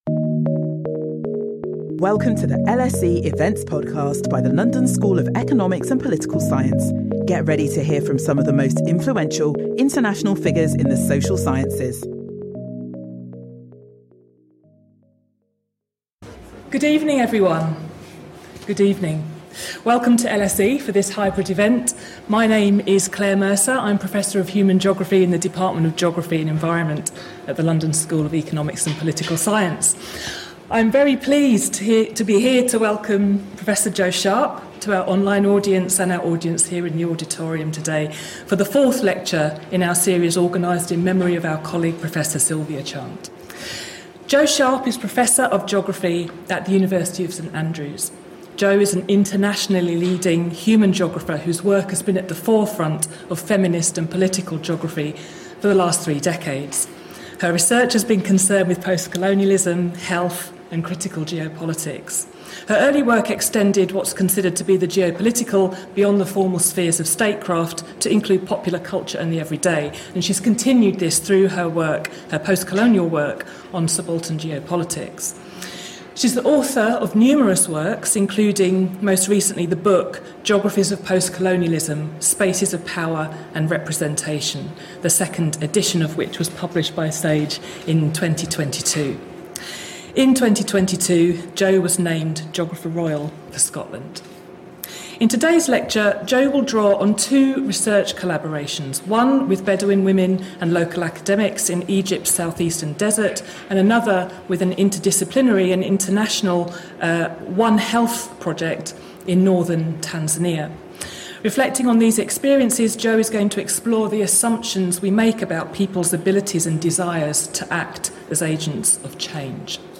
Join us for the Sylvia Chant Lecture which this year will be delivered by Jo Sharp, Geographer Royal for Scotland.